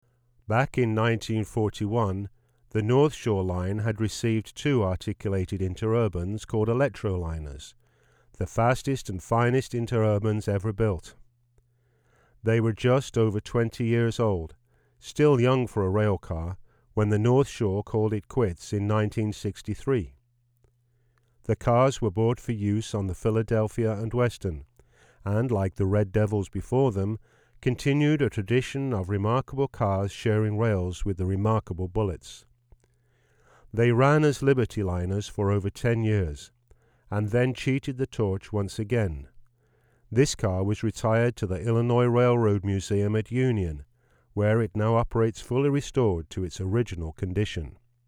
Tags: Voice Talent st.patrick saint patrick Voice actor